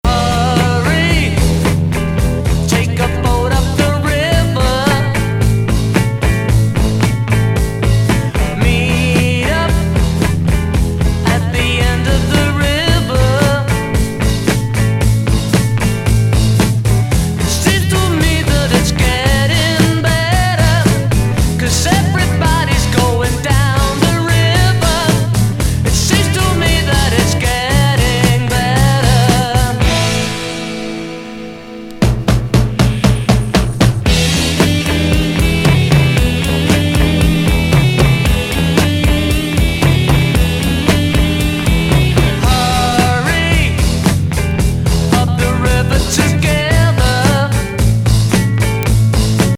カルトUKハードロック/サイケ・グループ唯一アルバム71年作!ファジーなギター